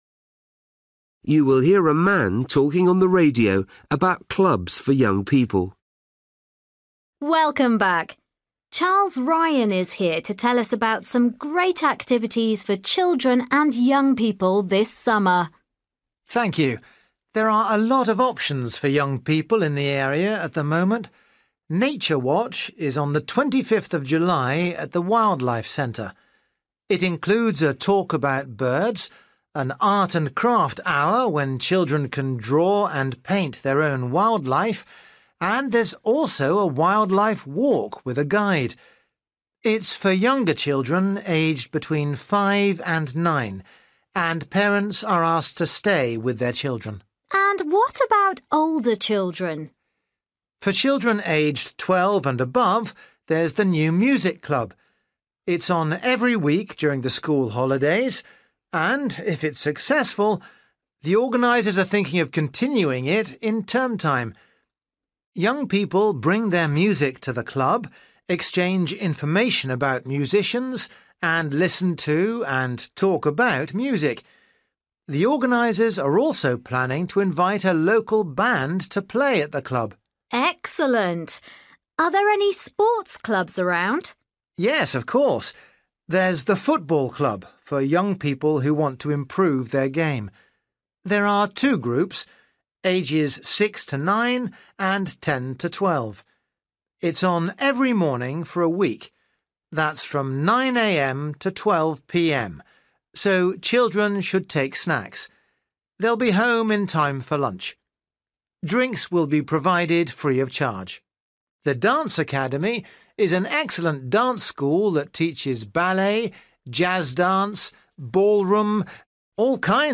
You will hear a man talking on the radio about clubs for young people.